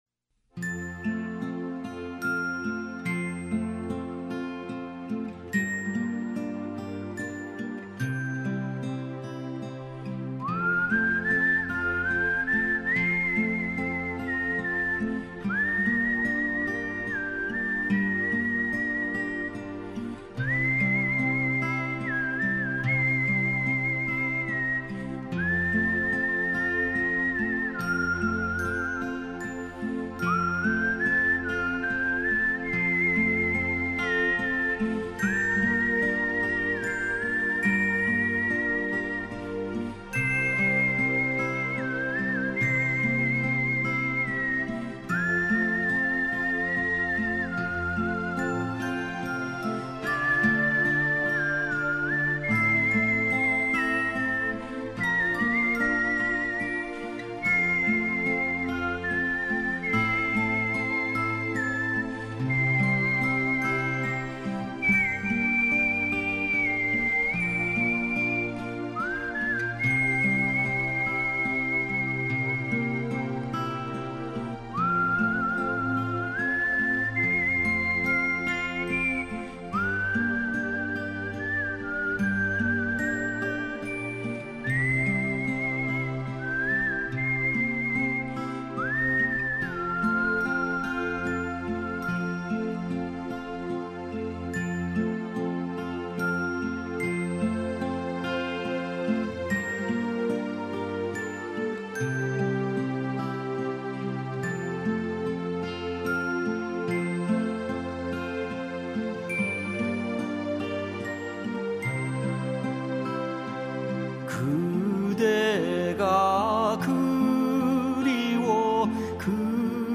가락이 너무 아름다워 눈물이 맺힙니다.